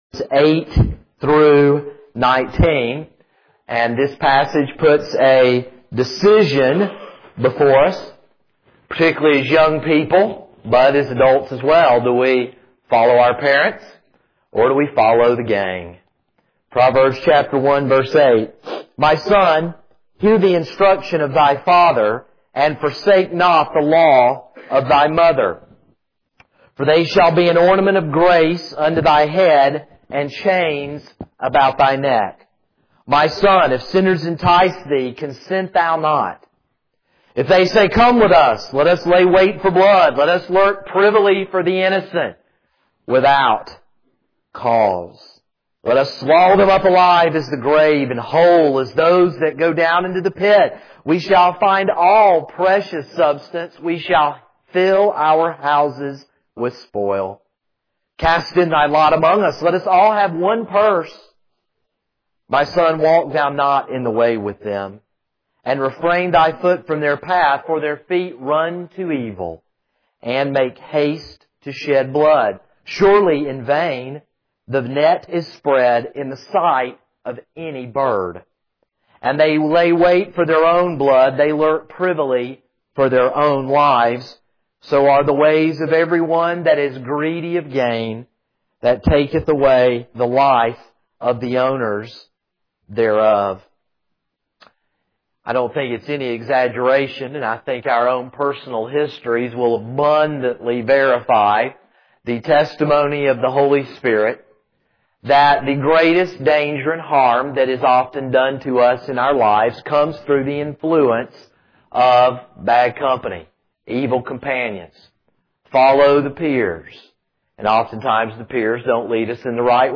This is a sermon on Proverbs 1:8-19.